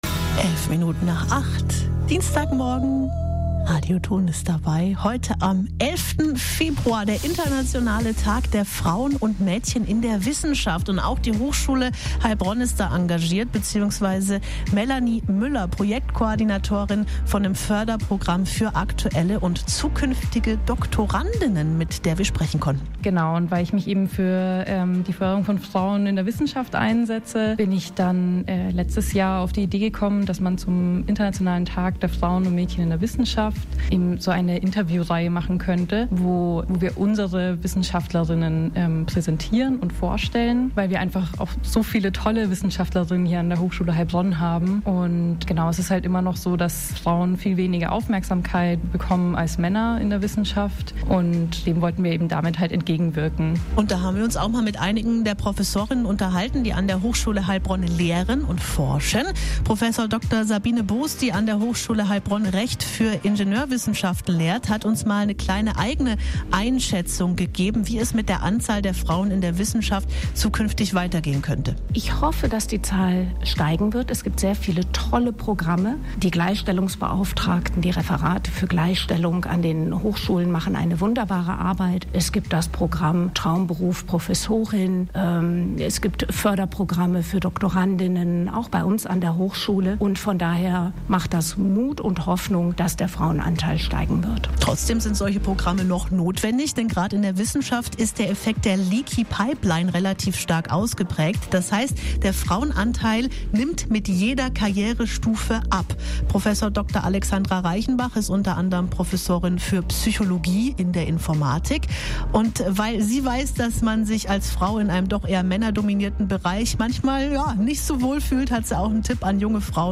Radio-Interview inkl. Instagram-Beitrag mit mir und meinen Kolleginnen zur Ausstellungsreihe “Frauen in der Wissenschaft” (Februar 2025, Radio Ton)